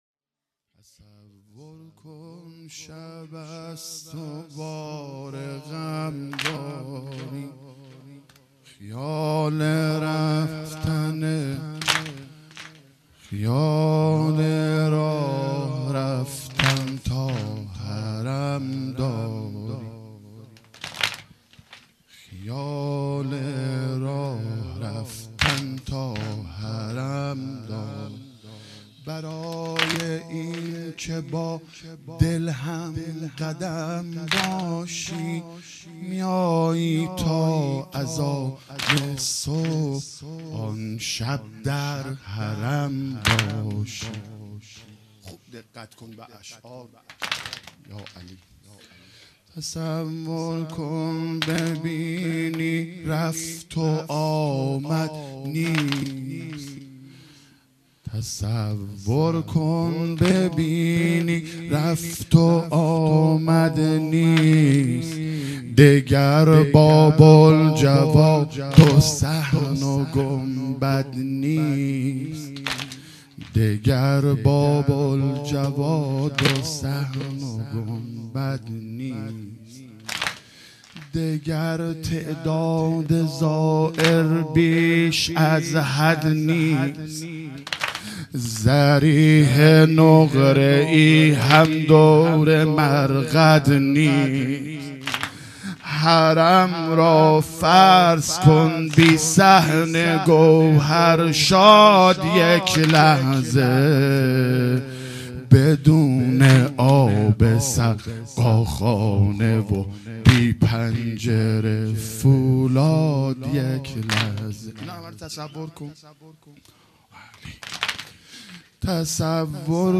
هیئت مکتب الزهرا(س)دارالعباده یزد - واحد | تصور کن شب است و بار غم داری مداح